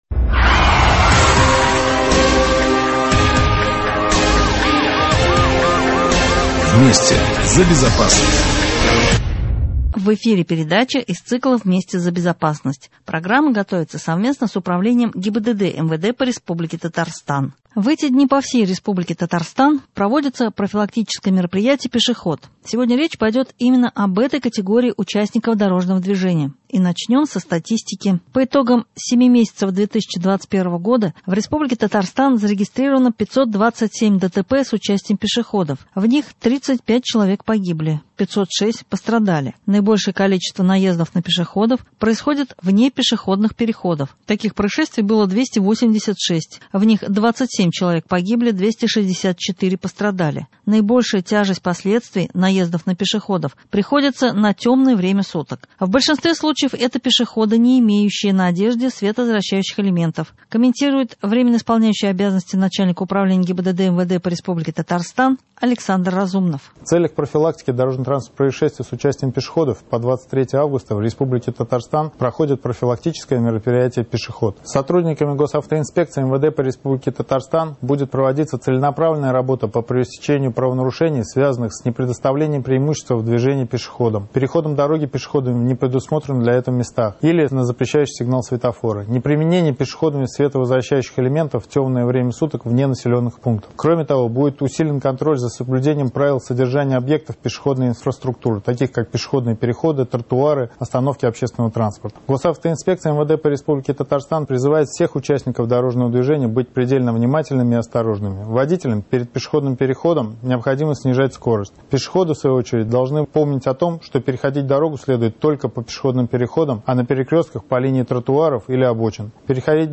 Эти вопросы наши корреспонденты задали в разных городах и районах Татарстана.